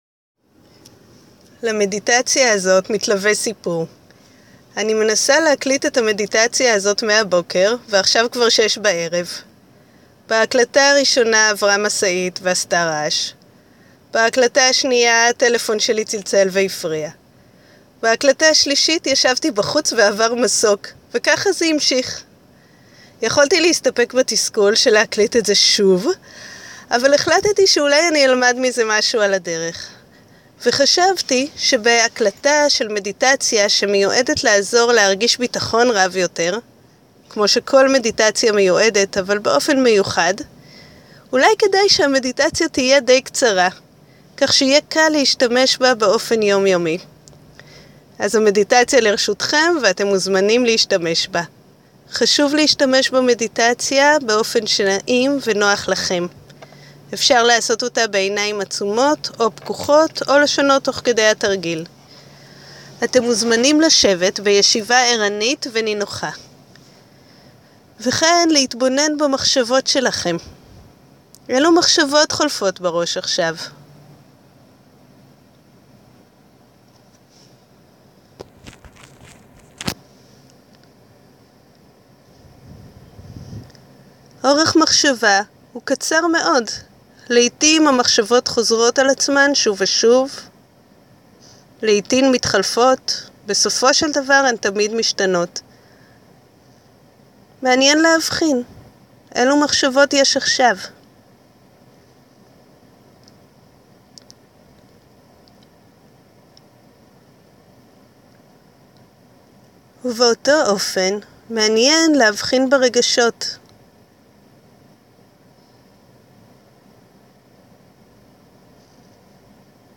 מדיטציית מיינדפולנס – לייצר ביטחון בלב הסערה